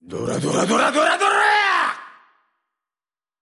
Voice Actor Shinichiro Miki
Voice Lines